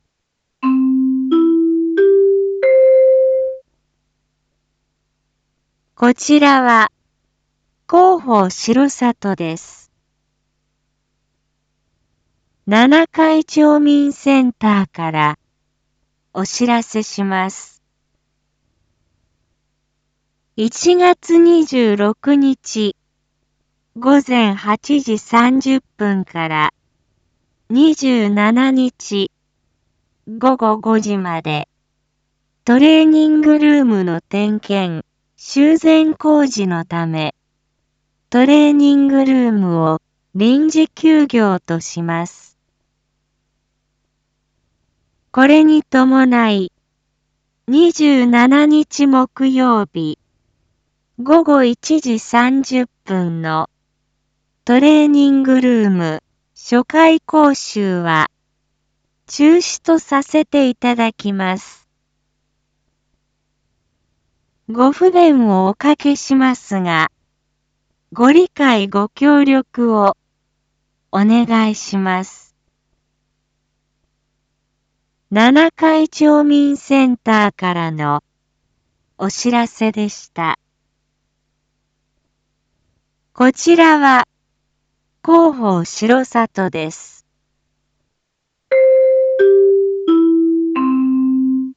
一般放送情報
Back Home 一般放送情報 音声放送 再生 一般放送情報 登録日時：2022-01-26 07:01:38 タイトル：R4.1.26 7時 インフォメーション：こちらは、広報しろさとです。